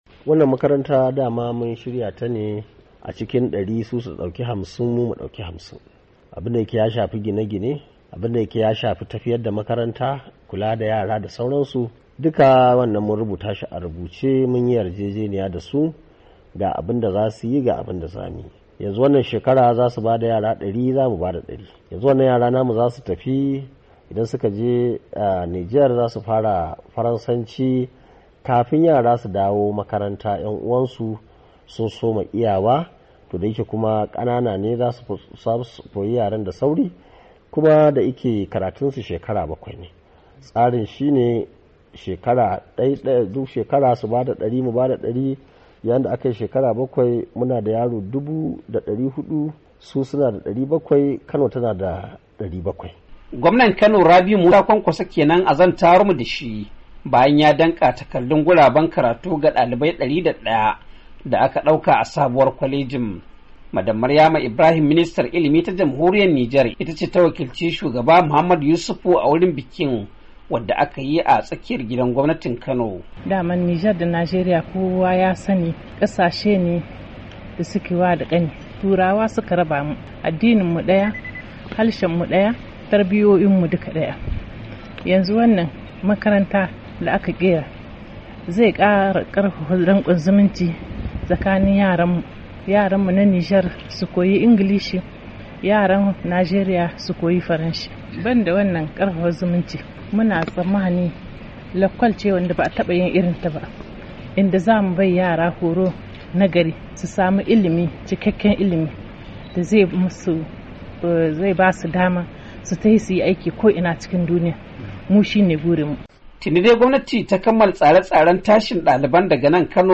Rahoton Makarantar Hadin Guiwa tsakanin Jihar Kano da Nijar - 2:04